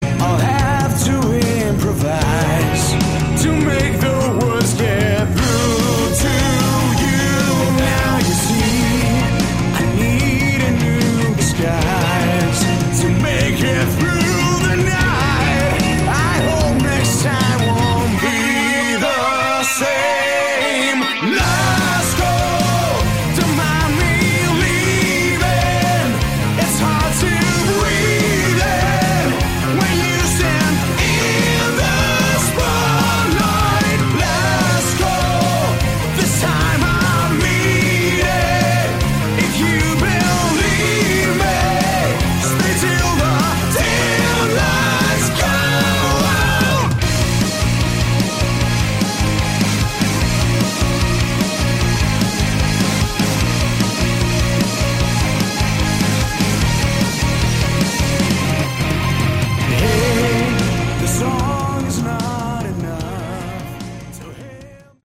Category: Hard Rock
Never heard such bad bad voice!!!derrible!!!!